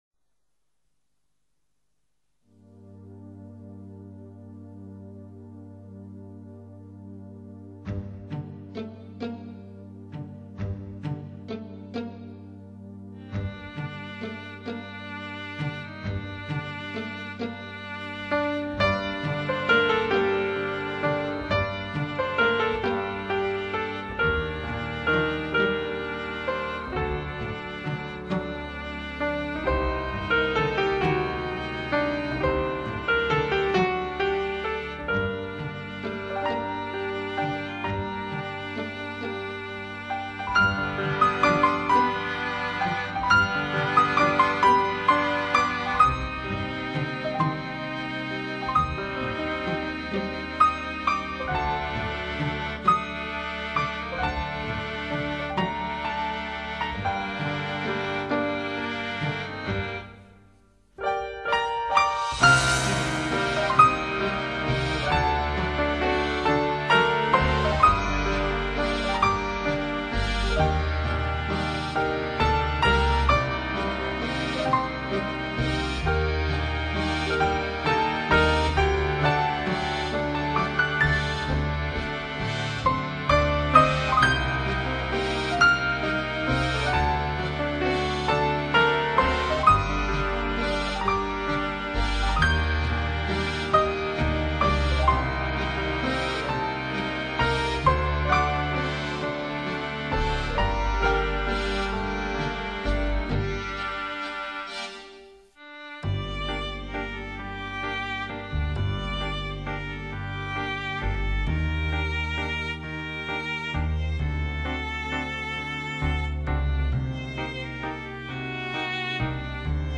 浪漫 怀旧 无奈 淡淡的忧伤